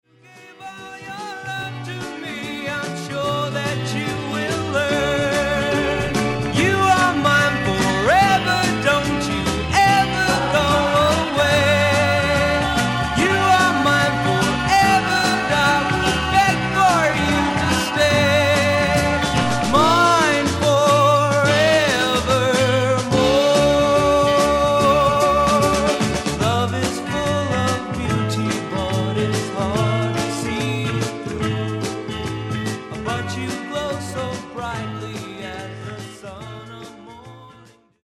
SOFT ROCK / PSYCHEDERIC POP